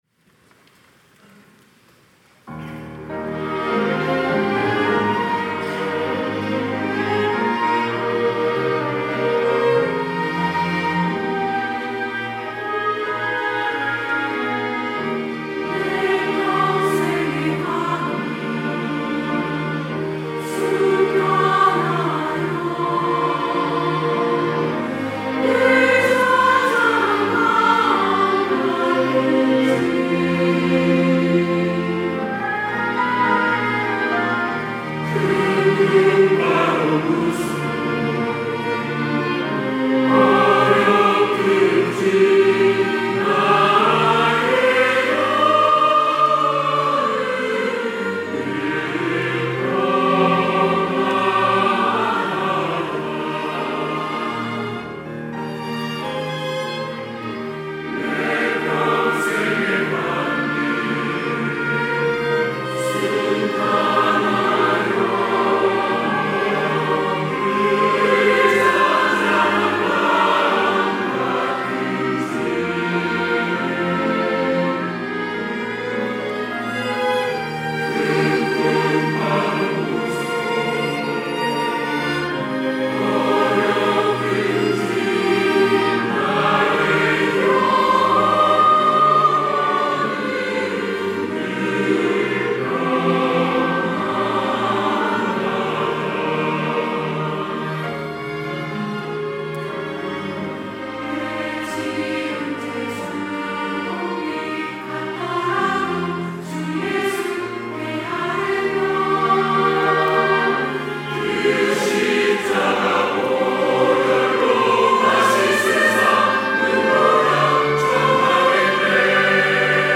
호산나(주일3부) - 내 평생에 가는 길
찬양대